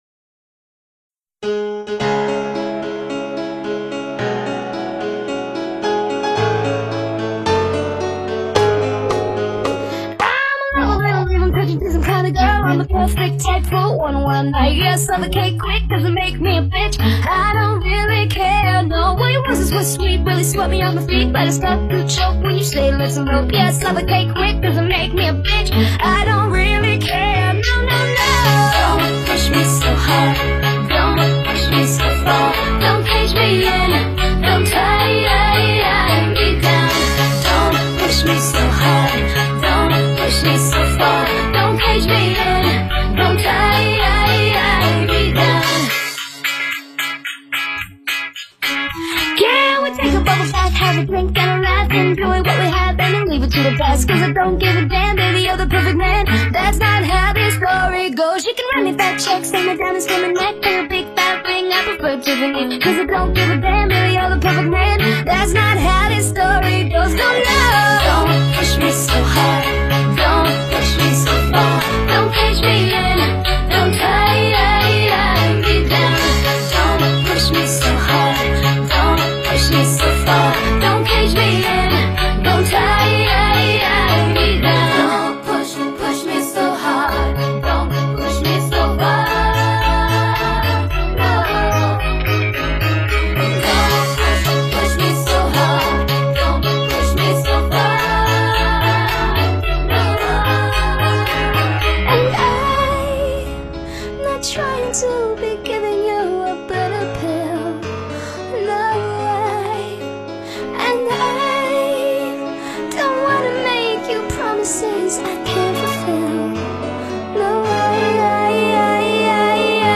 每分钟110拍